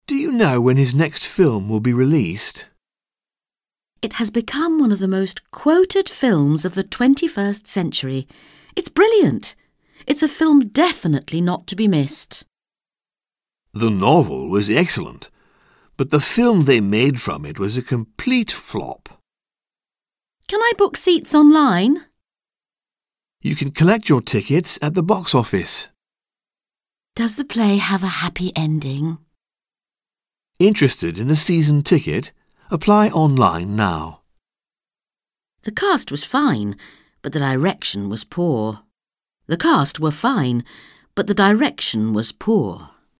Un peu de conversation - Le cinéma, le théâtre, la musique